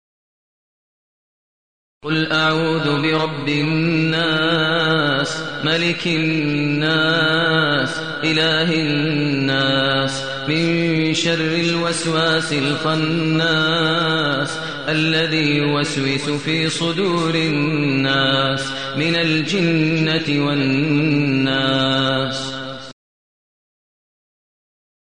المكان: المسجد النبوي الشيخ: فضيلة الشيخ ماهر المعيقلي فضيلة الشيخ ماهر المعيقلي الناس